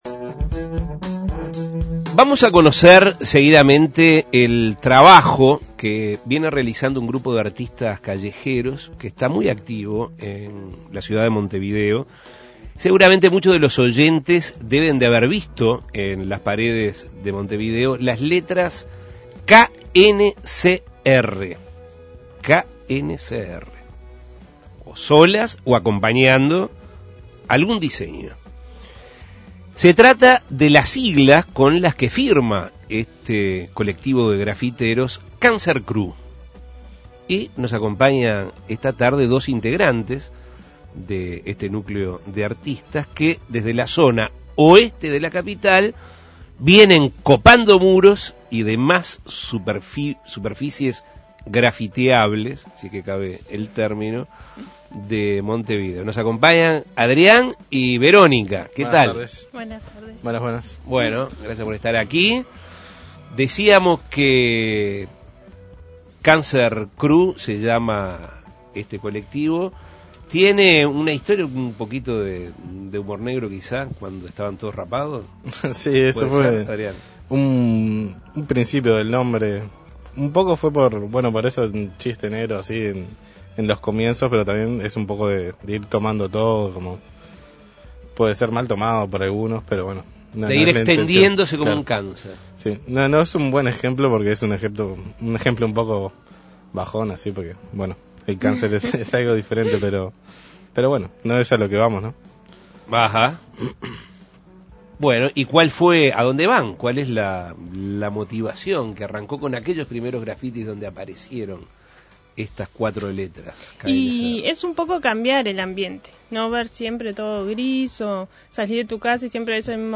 Entrevistas Graffiteros por Montevideo Graffiteros por Montevideo Graffiteros por Montevideo Graffiteros por Montevideo Imprimir A- A A+ Por las calles de Montevideo se pueden ver murales con la insignia KNCR.